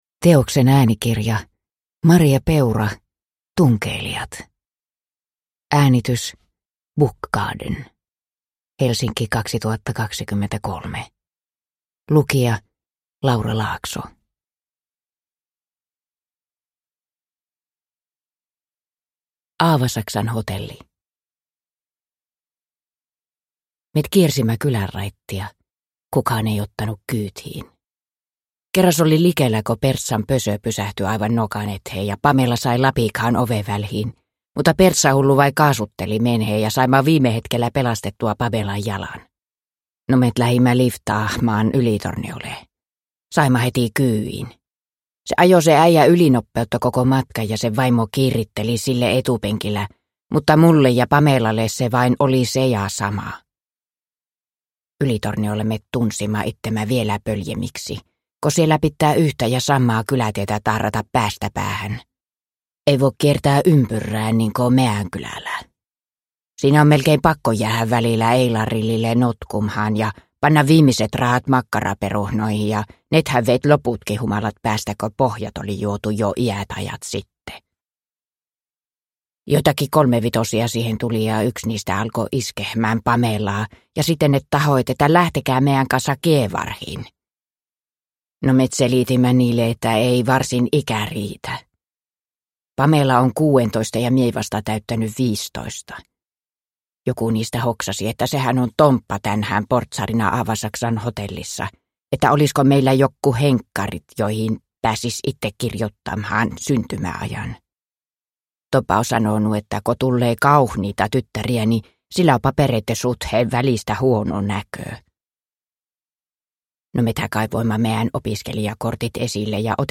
Tunkeilijat (ljudbok) av Maria Peura